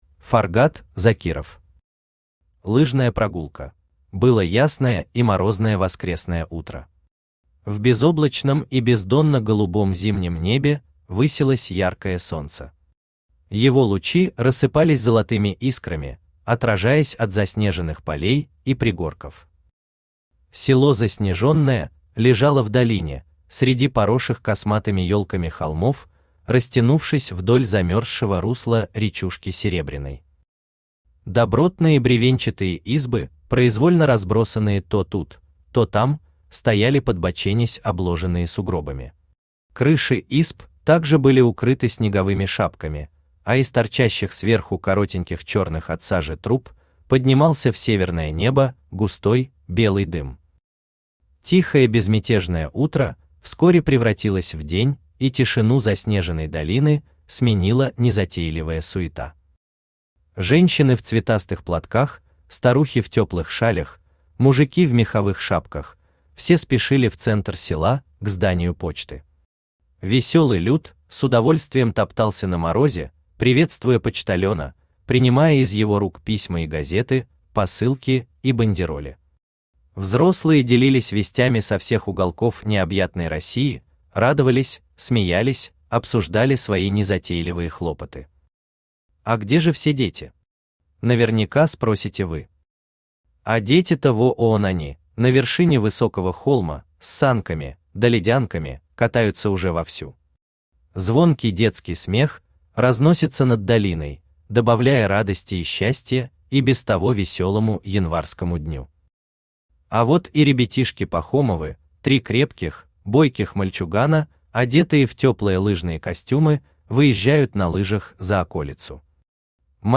Aудиокнига Лыжная прогулка Автор Фаргат Закиров Читает аудиокнигу Авточтец ЛитРес.